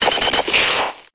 Cri de Taupiqueur dans Pokémon Épée et Bouclier.